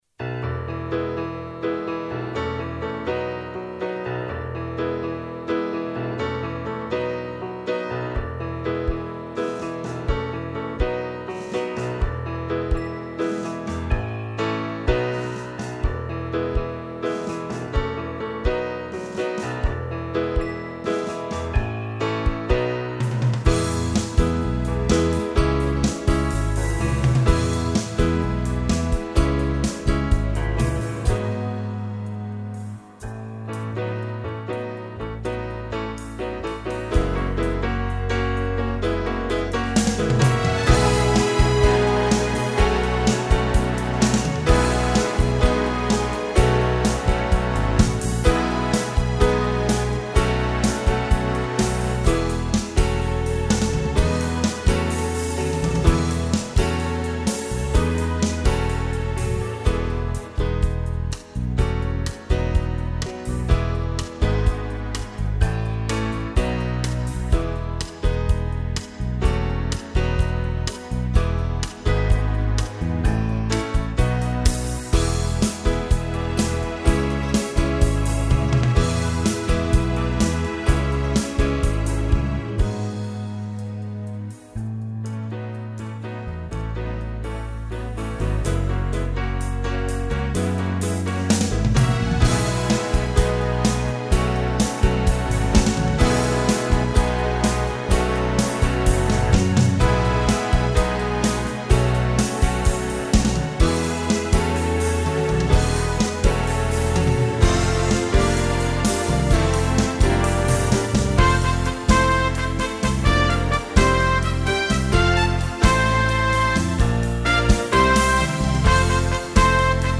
Genre: Oldies.